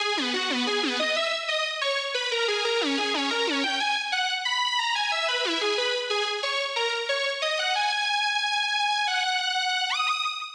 Hardcore
レイヤーを切ったリード部分だけで聴いていただきましょう。